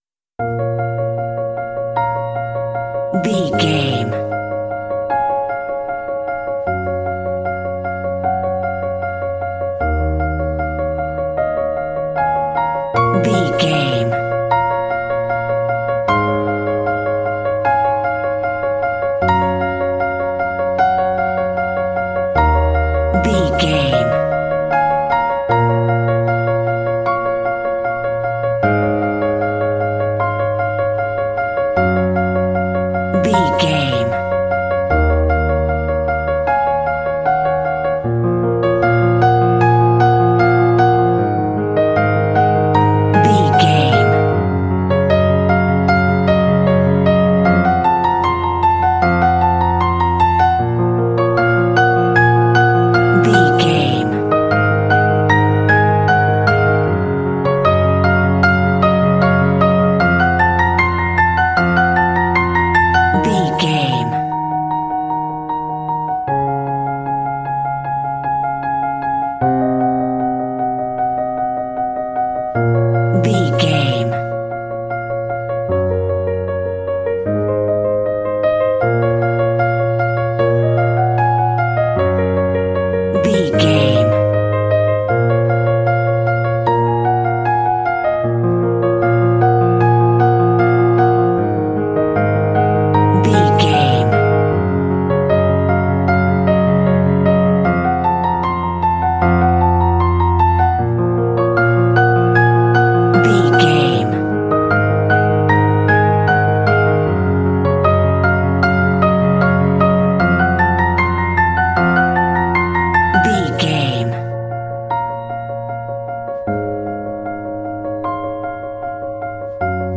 Epic / Action
Fast paced
Aeolian/Minor
B♭
epic
cinematic
classical
underscore